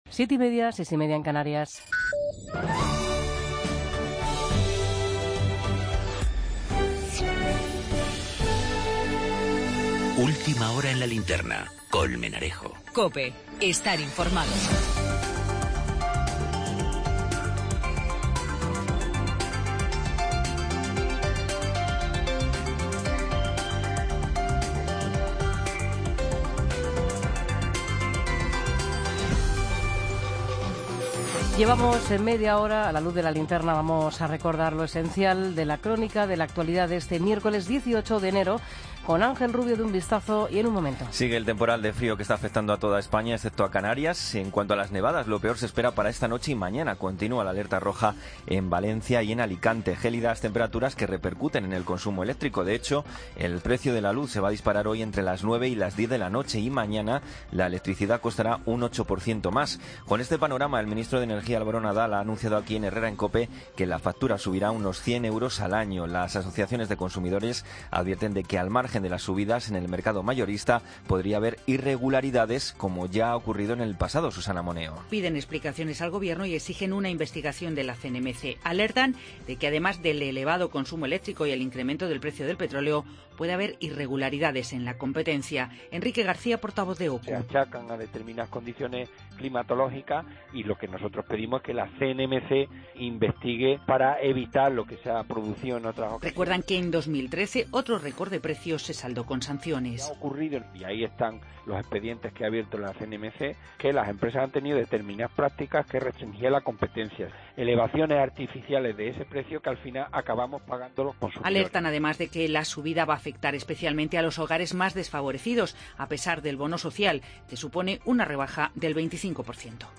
Ronda de corresponsales.
Entrevista al director Raúl Arévalo y a Antonio de La Torre, protagonista de la película "Tarde para la ira".